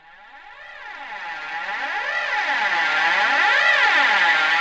K-4 Lo Cymbal FX.wav